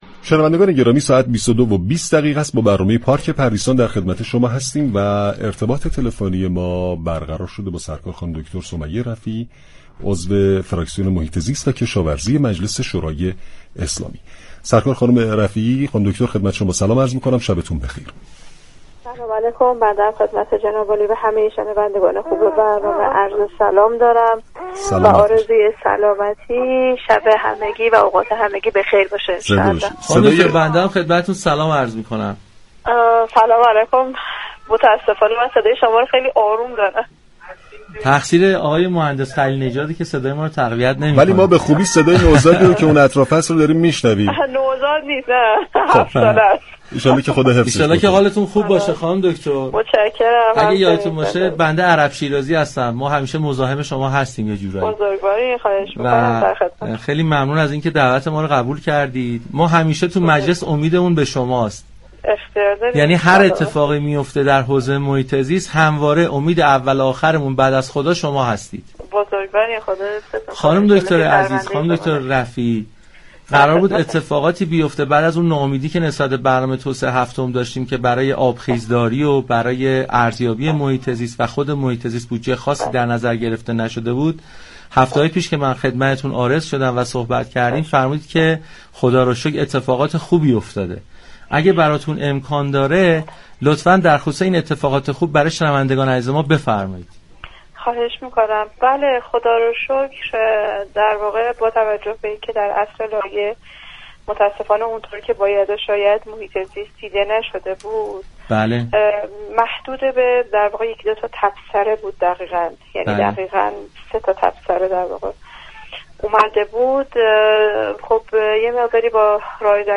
به گزارش پایگاه اطلاع رسانی رادیو تهران، سمیه رفیعی عضو فراكسیون محیط زیست و كشاورزی مجلس شورای اسلامی در گفت و گو با «پارك پردیسان» اظهار داشت: متاسفانه در لایحه پنج ساله هفتم، آنگونه كه باید توجهی به محیط زیست نشده و محدود به 3 تبصره در این حوزه بود.